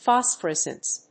/‐snt(米国英語)/
アクセント・音節phòs・pho・rés・cent 発音記号・読み方/‐snt/
音節phos･pho･res･cent～･ly発音記号・読み方fɑ̀sfərés(ə)nt|fɔ̀s-